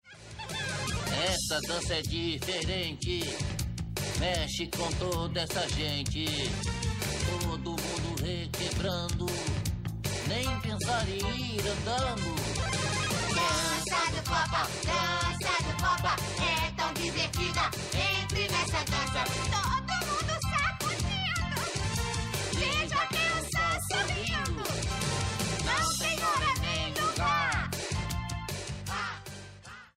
Music Sample